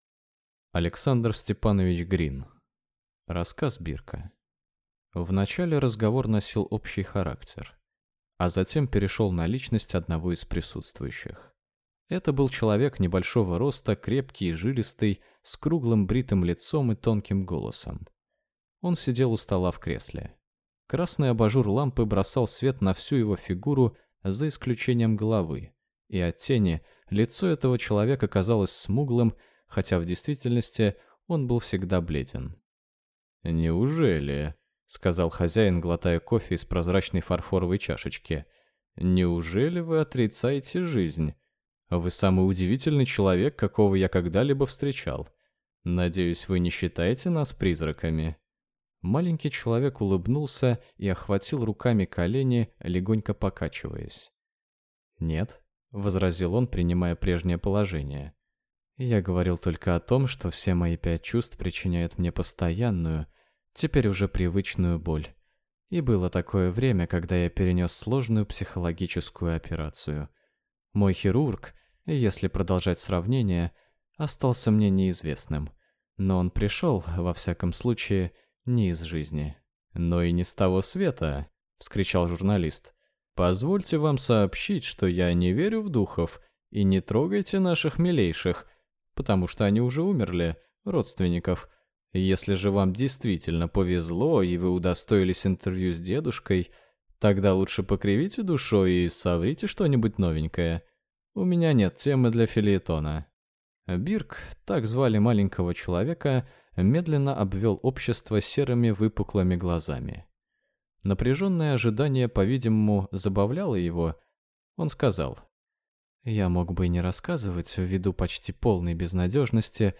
Аудиокнига Рассказ Бирка | Библиотека аудиокниг